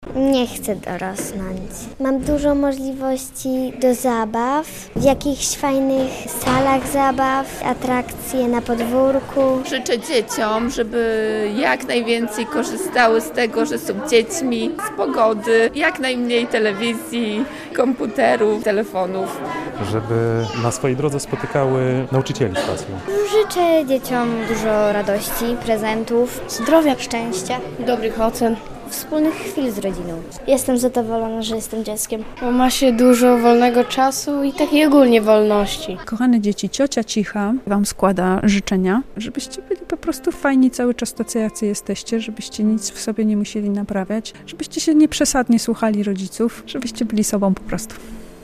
Dzień Dziecka - relacja